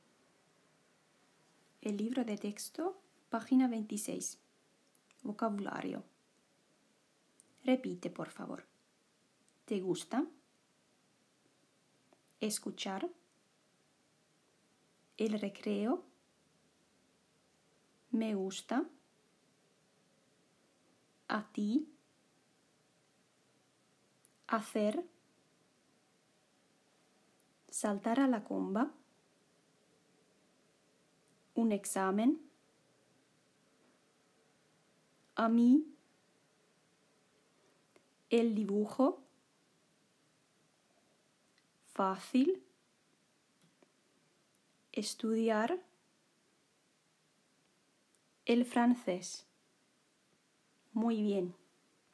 Lue ensin ääneen keltainen sanastolaatikko espanjaksi open perässä.